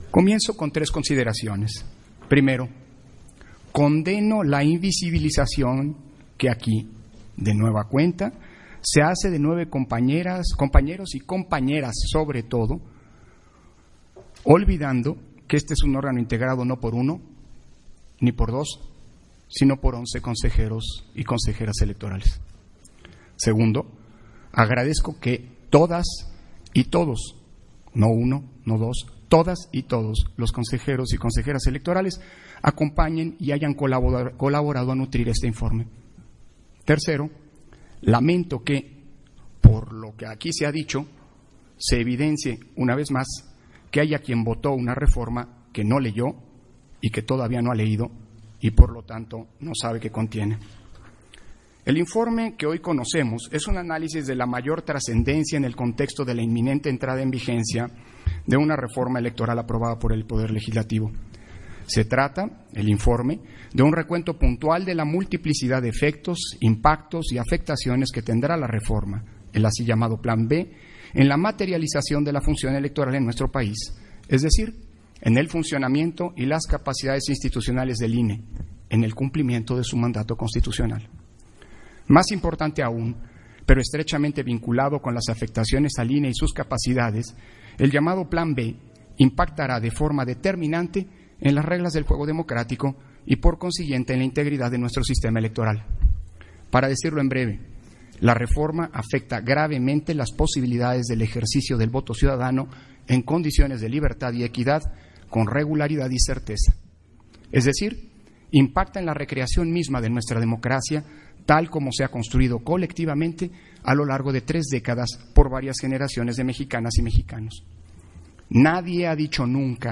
250123_AUDIO_-INTERVENCIÓN-CONSEJERO-PDTE.-CÓRDOVA-PUNTO-1-SESIÓN-EXT. - Central Electoral